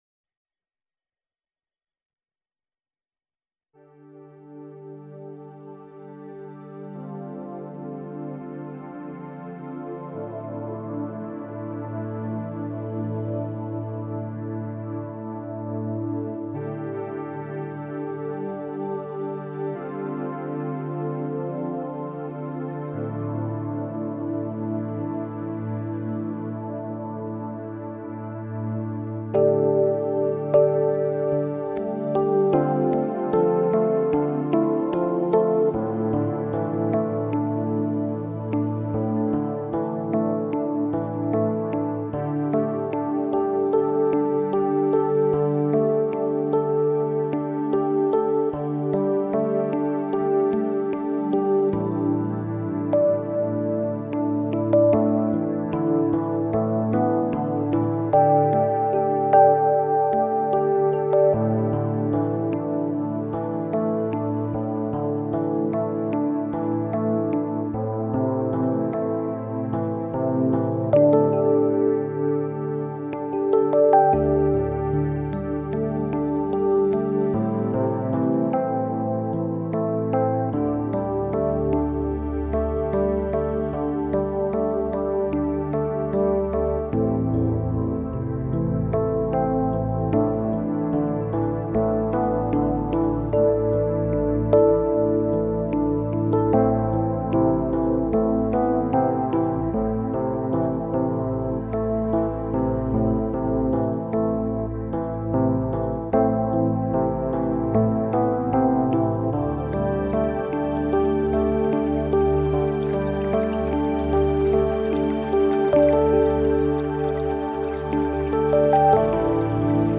因此我们可以于他的音乐中听见超脱与灵性，远离浮躁和奢华，于最安谧的境界中细细品味音乐的本真，收获圆融与喜悦。